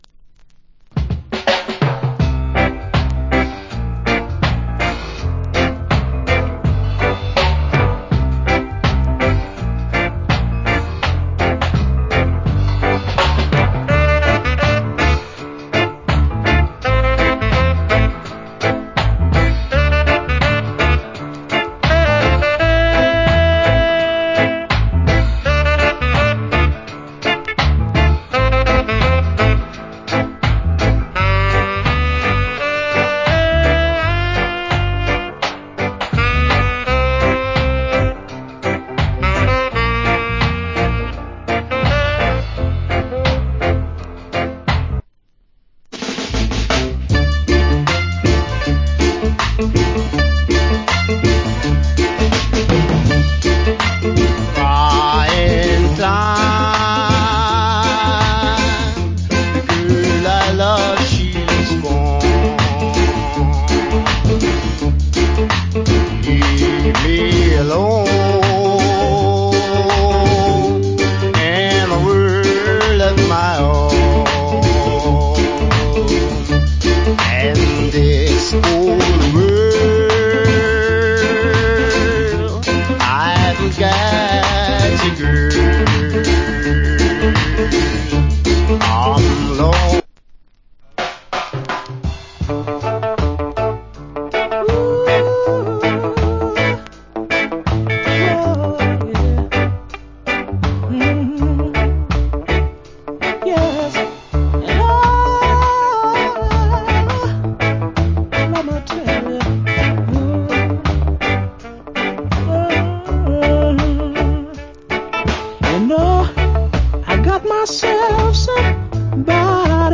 Top Rock Steady.